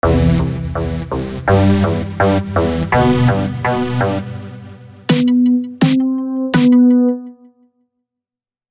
melodie: (kdyby někomu nestačila amateréská ukázka)
táda tata (vyšší od 2 tóny)